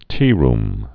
(tērm, -rm)